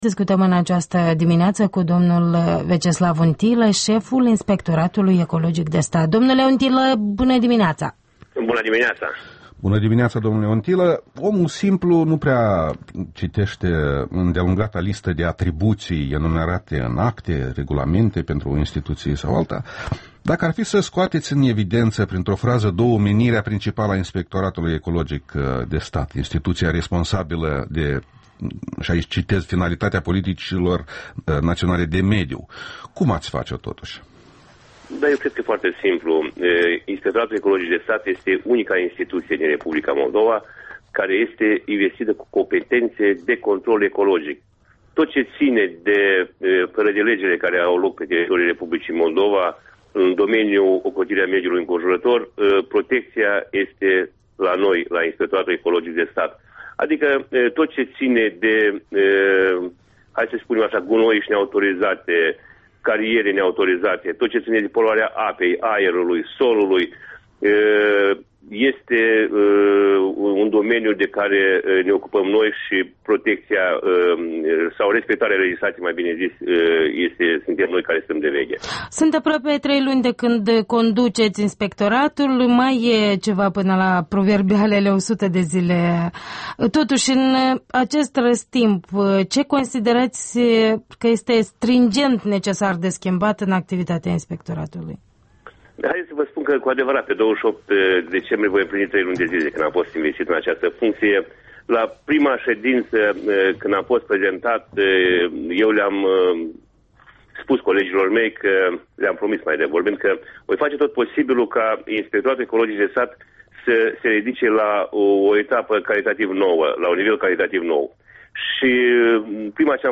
Interviul dimineții: cu Veaceslav Untilă despre imperativele de ordin ecologic în Moldova